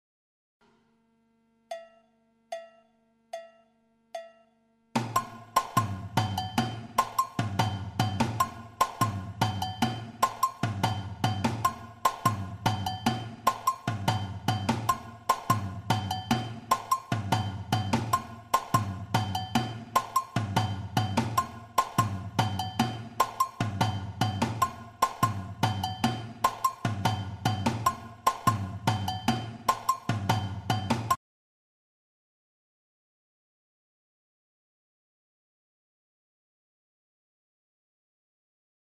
Un phrasé avec L'agogo
figure agogo samba